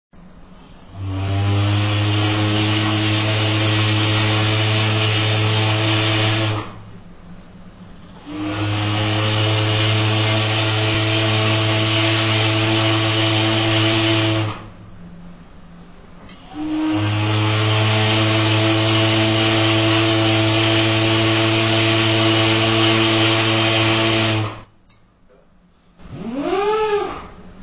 TYPHON